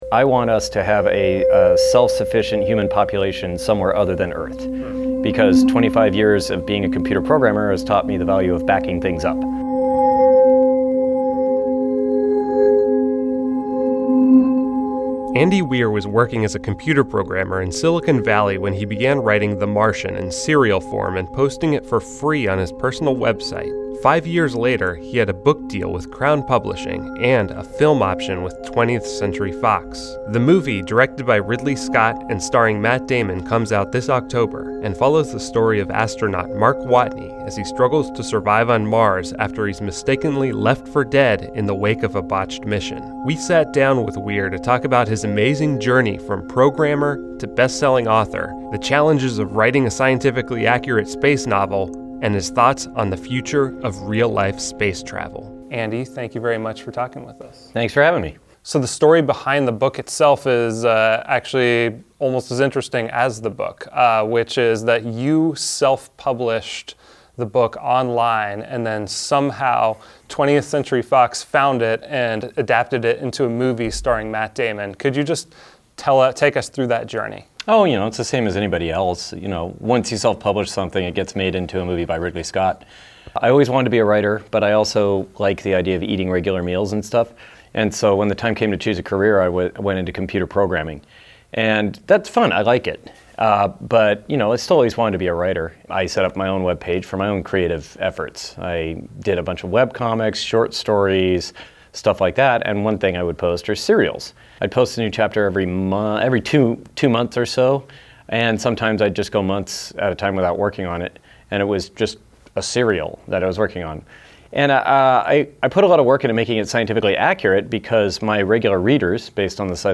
Q&A with the man who wrote the book behind the upcoming Hollywood film starring Matt Damon.